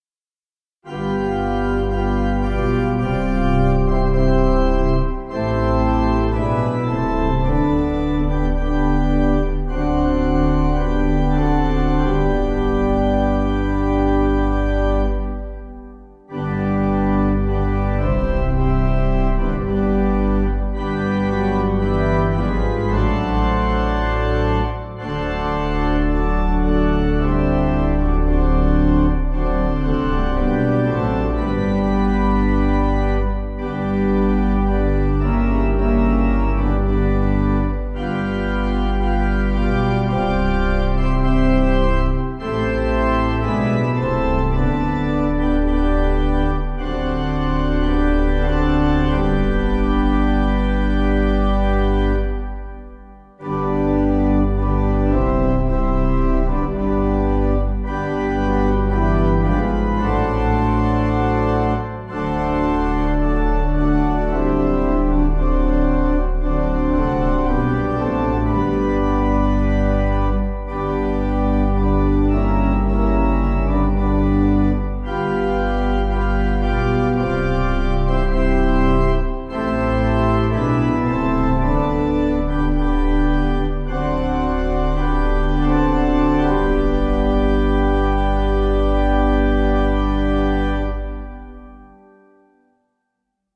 Audio files: MIDI,
Key: G Major